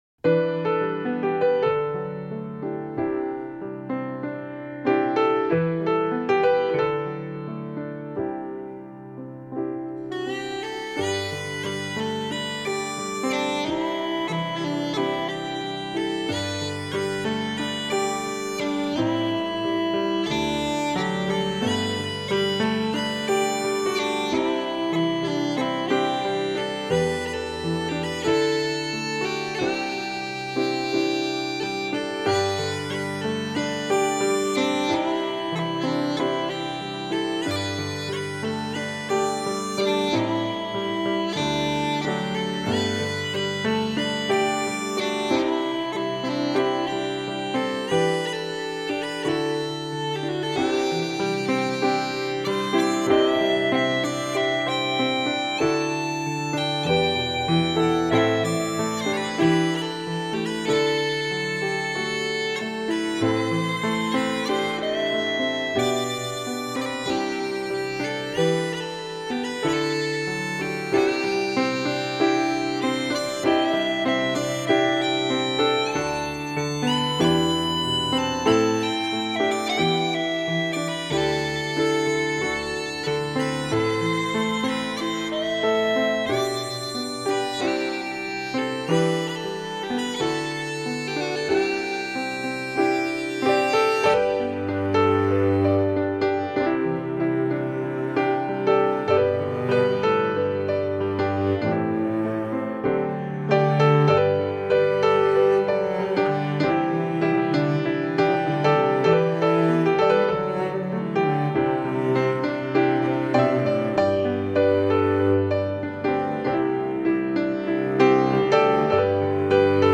1998 彷彿阵阵飘灑的稀雨，清涼的钢琴，悠扬的风笛……凯尔特的神秘在清冽的旋律中荡漾。
非常好的凯尔特音乐唱片。轻灵、音调优美的钢琴在风笛、小提琴、口哨等的配合下，把爱尔兰丛林中的美景栩栩如生地 表现出來。